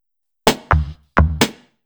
Index of /VEE/VEE2 Loops 128BPM
VEE2 Electro Loop 159.wav